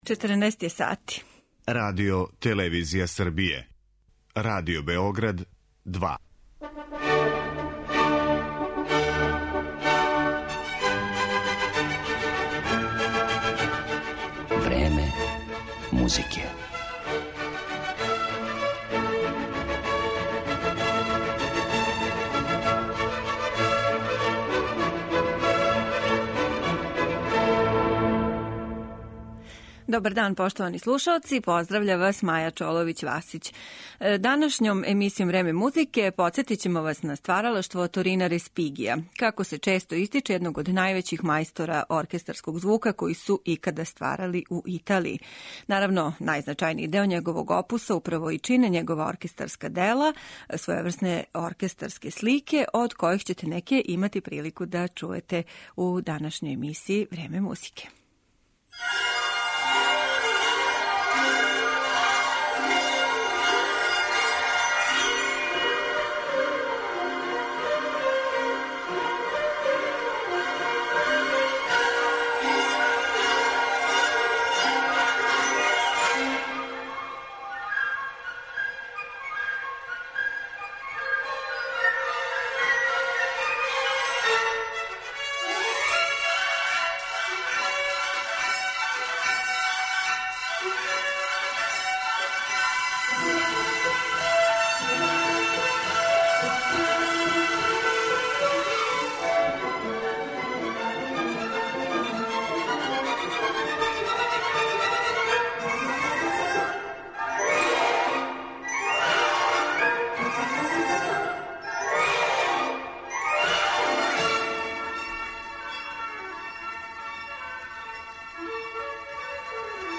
Емисија је посвећена италијанском композитору Оторину Респигију - изврсном познаваоцу оркестрације и творцу неких од најколористичнијих дела за симфонијски оркестар почетка XX века.